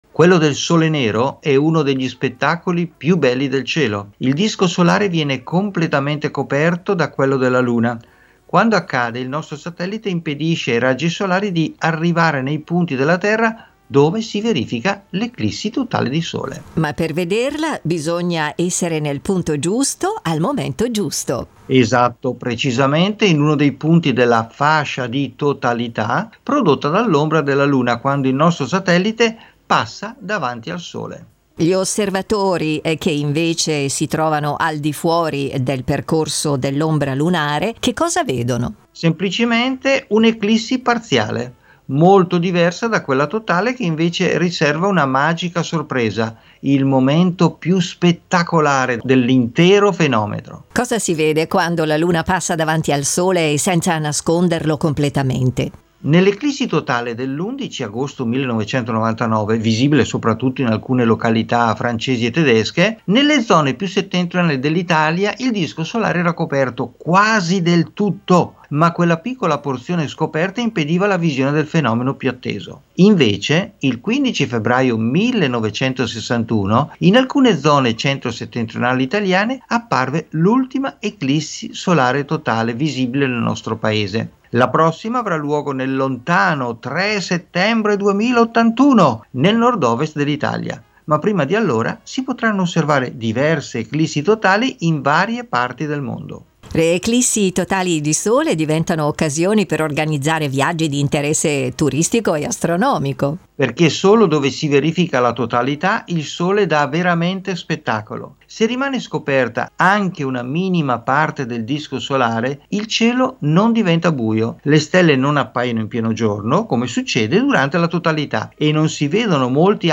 Il progetto “Idiomi celesti” promuove l’osservazione ad occhio nudo del cielo stellato con testi scritti e letti ad alta voce in lingua ladina e in dialetto lumezzanese.